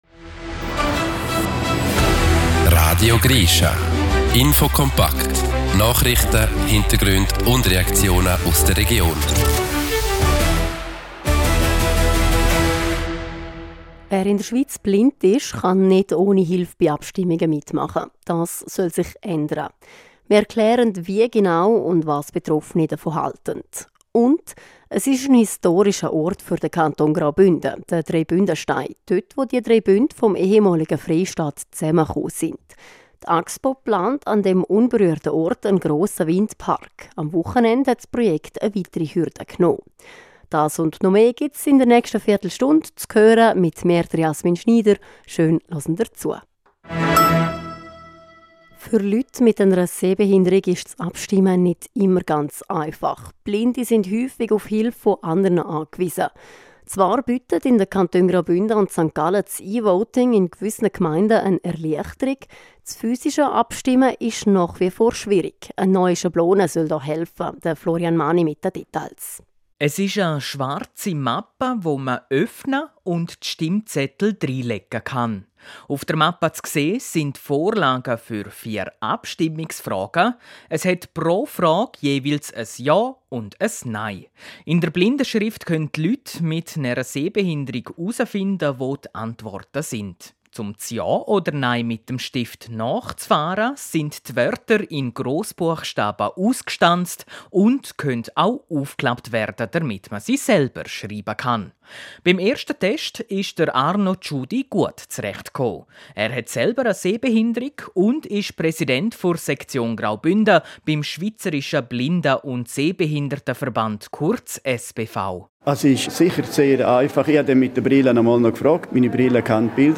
Ab November können Blinde und Menschen mit Sehbehinderung erstmals im Kanton Zürich ihr Abstimmungscouvert selbst ausfüllen. Ein Betroffener aus Graubünden ordnet ein, wie nutzerfreundlich diese Methode ist.